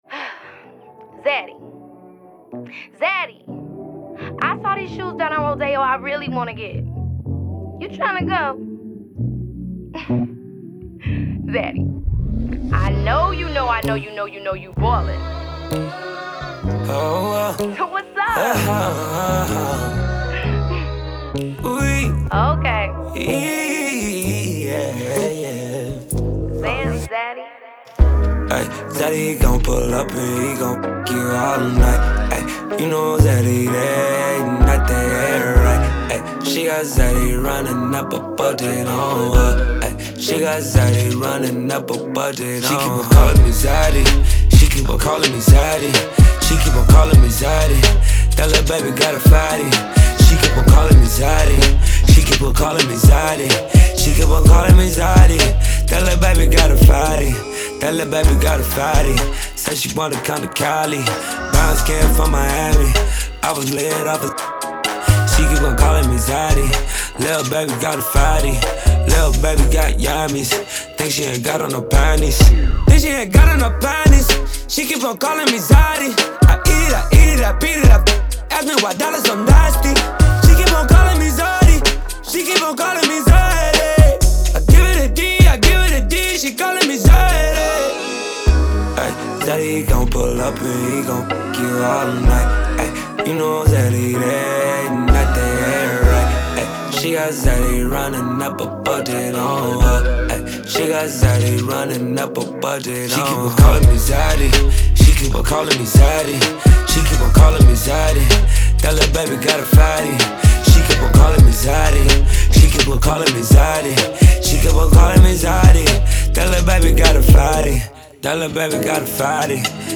SINGLESR&B/SOUL